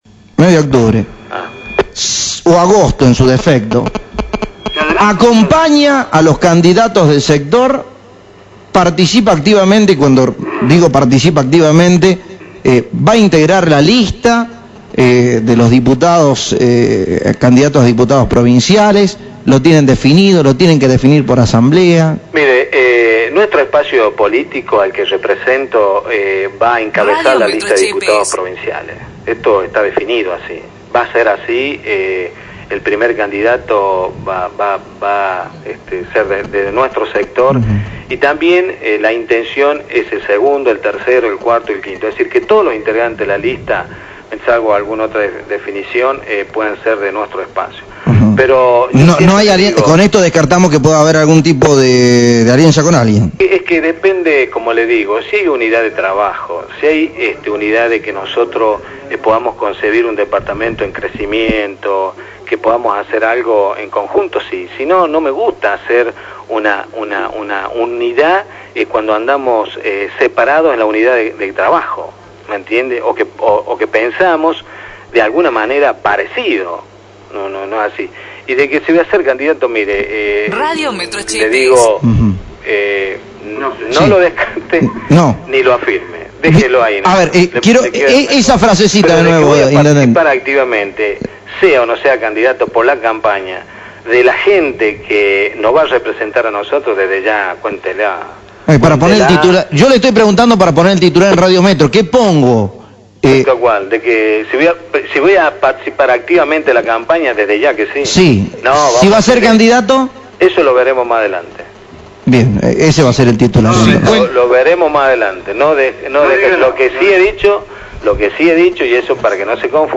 La entrevista que en primer término se refirió estrictamente a la continuidad de algunas obras y novedades del municipio tuvo un giro importante cuando se le consultó sobre algunos dichos de la diputada Oviedo, pero puntualmente sobre la posibilidad de que el actual titular de la comuna participe como candidato en las legislativas de octubre o agosto si se adelantan las elecciones.